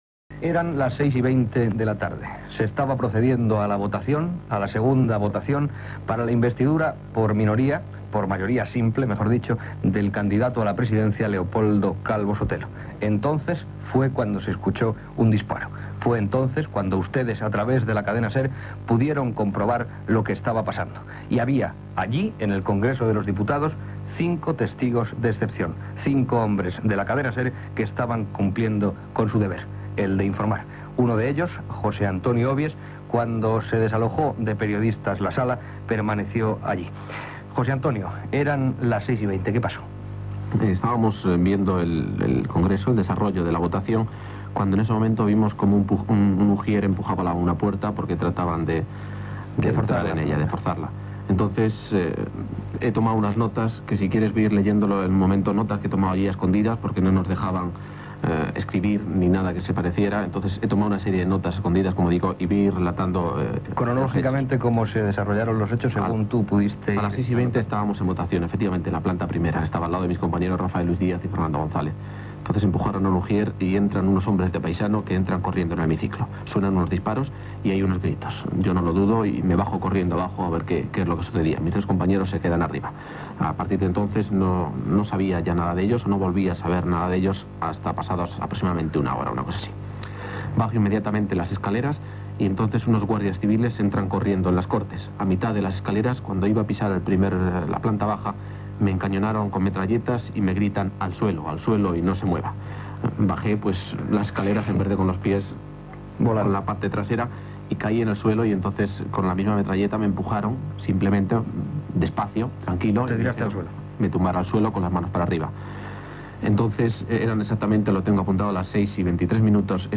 Gènere radiofònic Informatiu